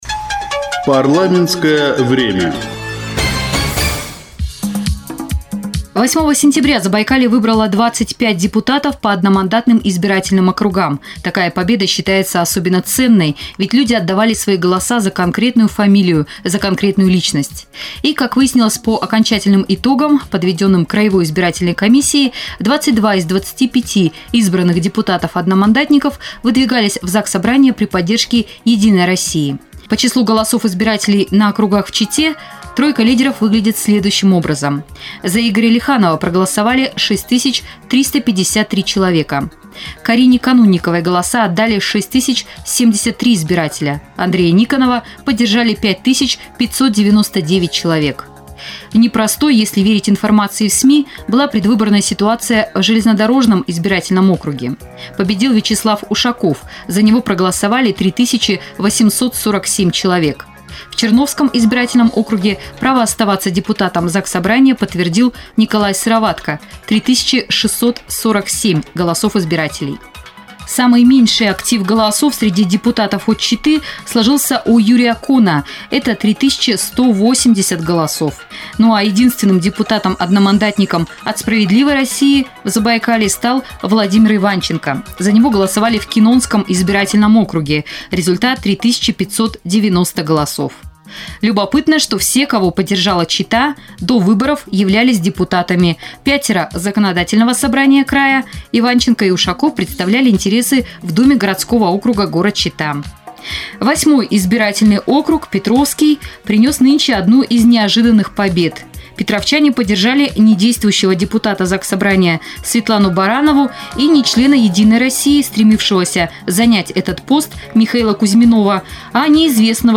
Региональные СМИ подводят итоги избирательной кампании и начинают знакомство с новичками политической сцены. С одним из них – независимым депутатом Геннадием Капустиным, победившим в Петровском одномандатном округе, побеседовала журналист